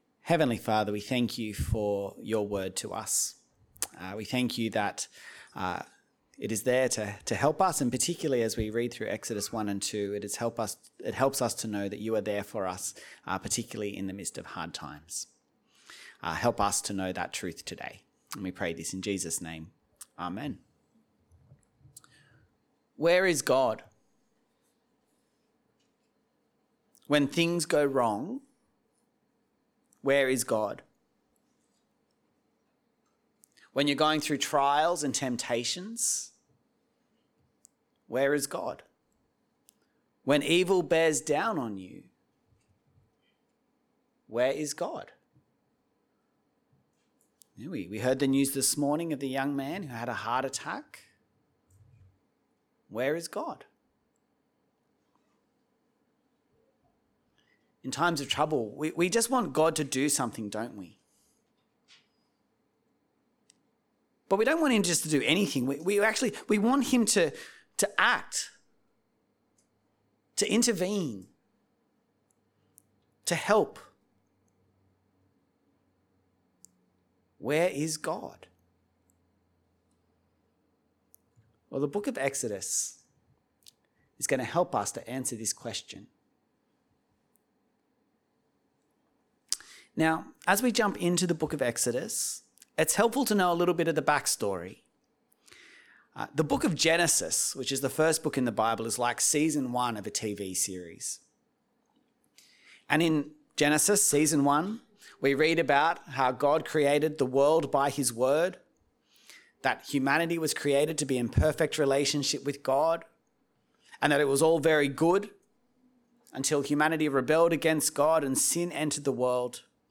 Sermon Series - St. James Anglican Church Kununurra